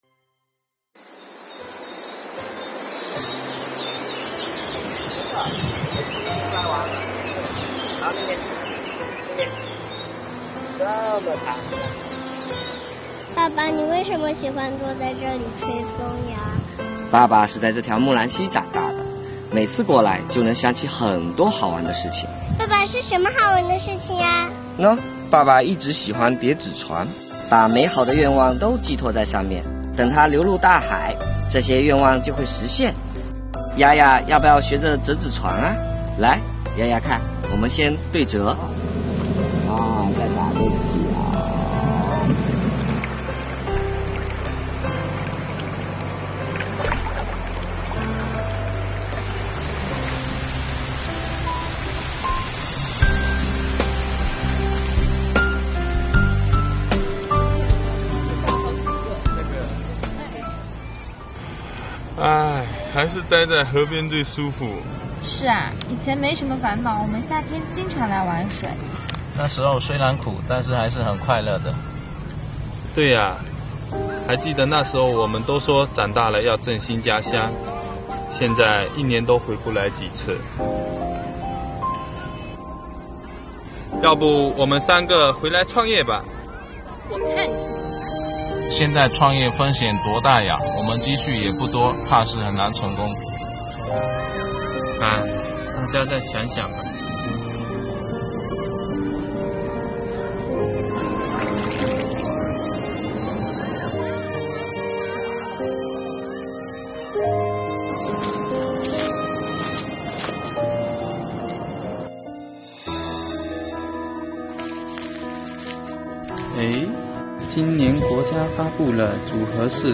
本作品摄影水平较为专业，长焦镜头的运用和景深控制得当，建议同类型作品表演上要更自然一些，并提高现场收音水平。